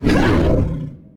attack1.ogg